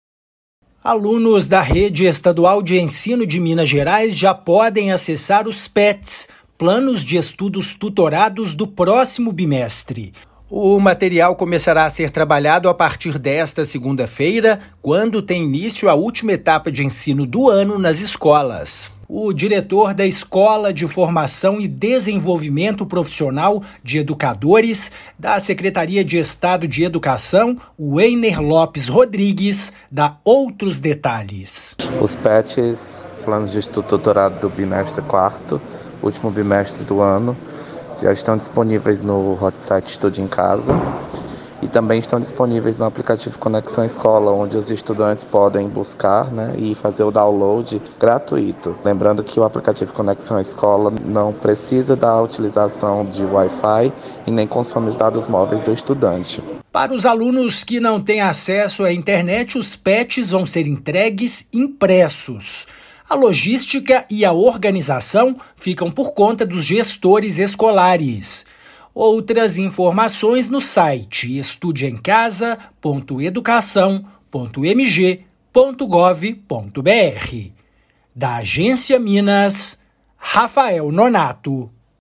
Material será utilizado a partir desta segunda-feira (18/10), data em que começa a próxima etapa de ensino nas escolas estaduais. Ouça a matéria de rádio.